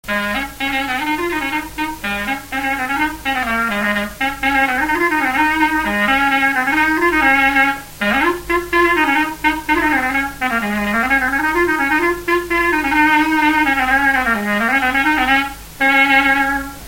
Résumé instrumental
danse : branle : avant-deux
Pièce musicale inédite